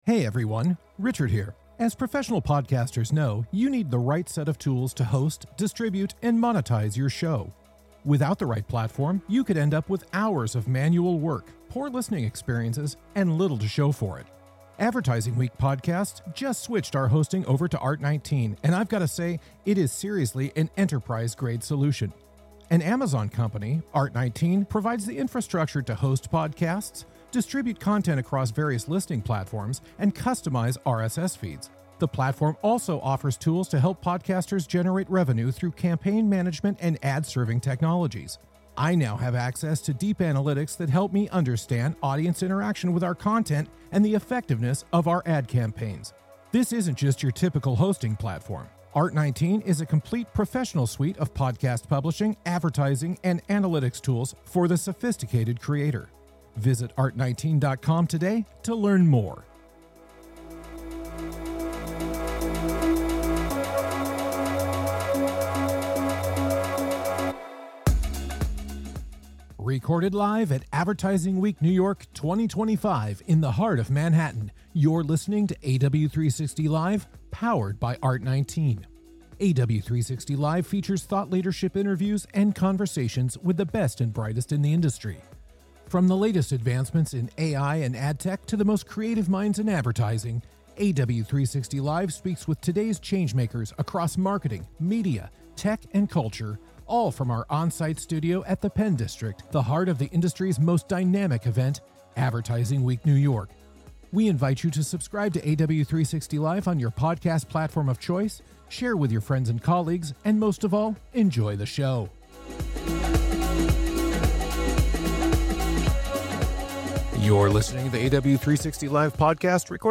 Recorded live at Advertising Week New York 2025, AW360 Live features interviews with today’s changemakers across marketing, media, tech, and culture. Discover groundbreaking ideas and soak up the energy of industry’s most dynamic event in this long-running one-on-one interview series.